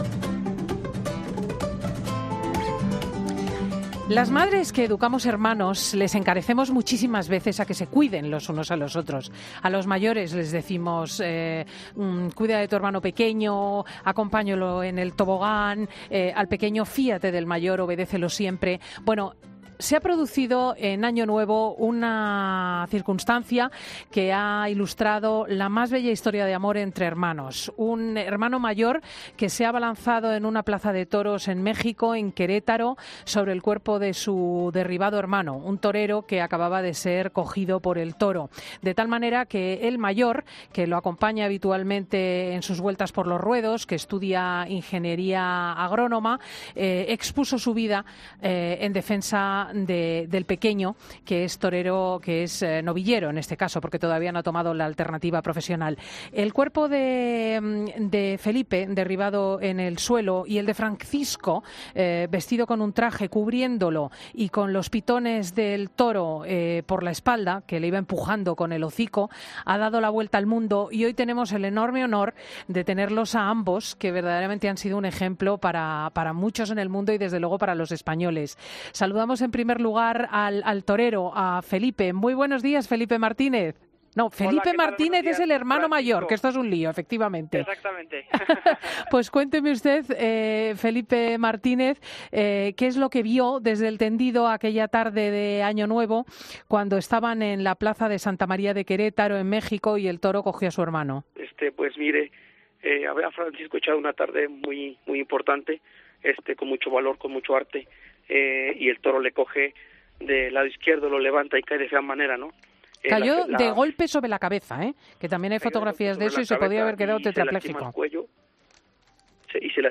En Fin de Semana han podido hablar con ambos hermanos.